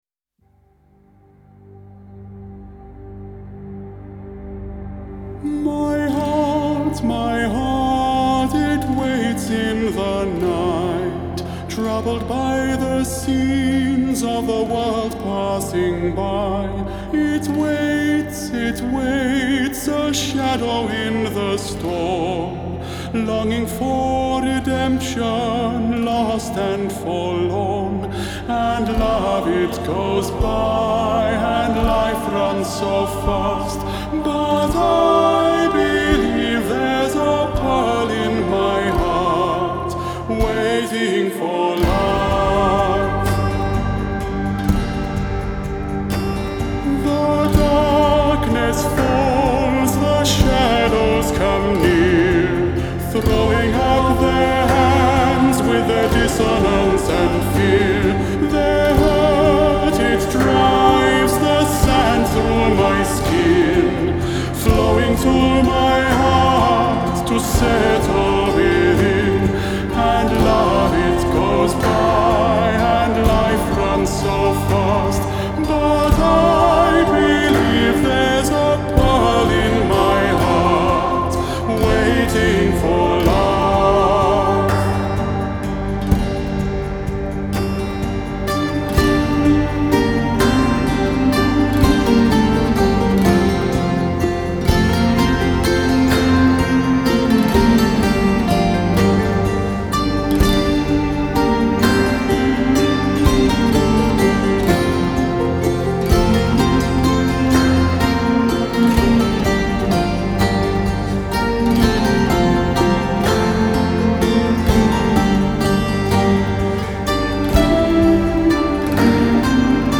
Hi-Res Stereo
Genre : Pop